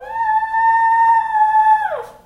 描述：沙吉回来了，她可以像任何人一样嚎叫。
声道立体声